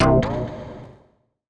Synth Stab 02 (C).wav